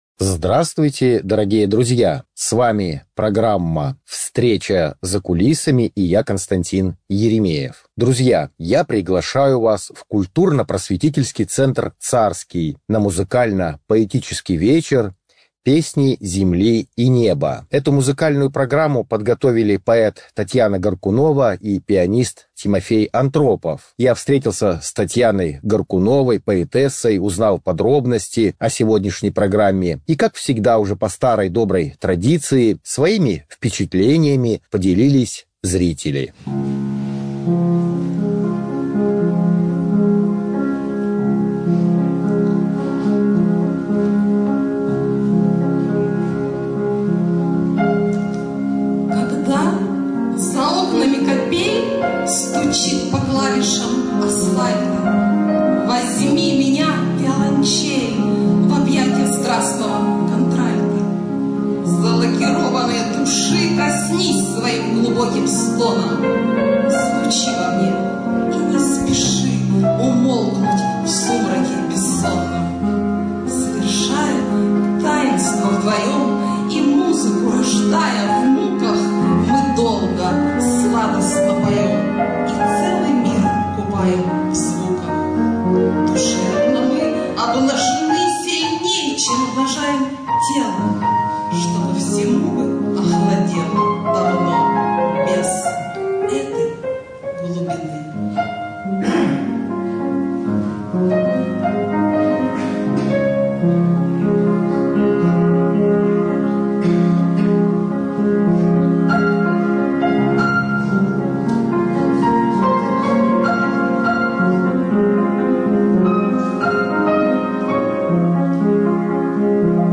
Поэтический Вечер
poeticheskij_vecher_pesni_zemli_i_neba.mp3